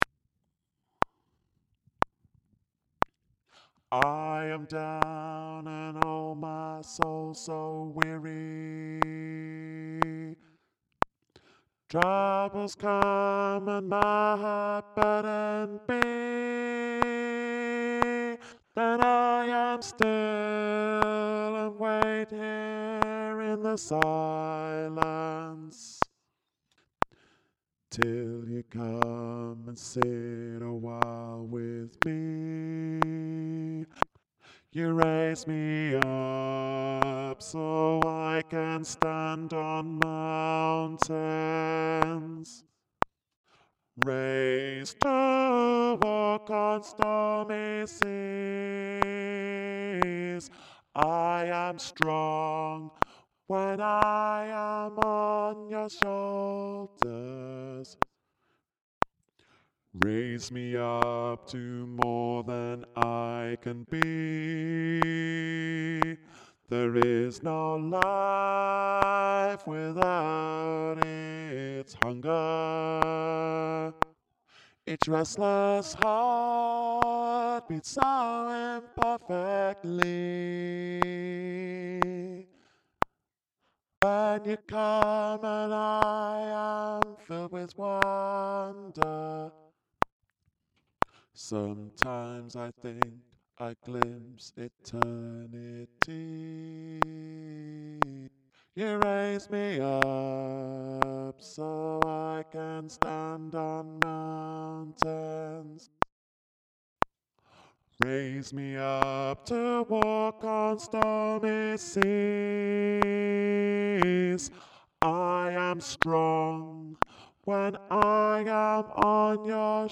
You Raise Me Up Bass
You-Raise-Me-Up-Bass.mp3